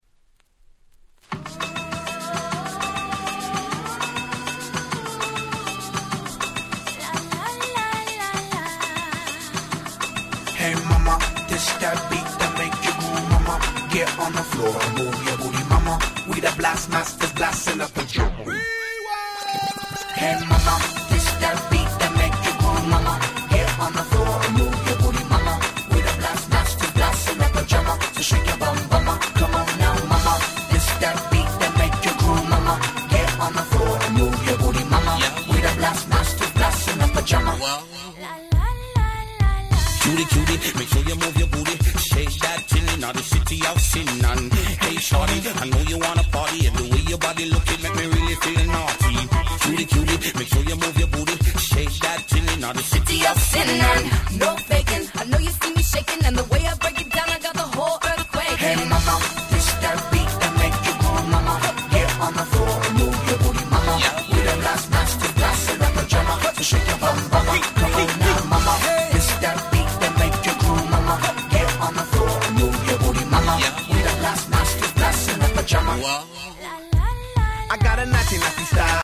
こちらのRemixはサビから始まるのでオリジナルよりも格段に使い易いです！
キャッチー系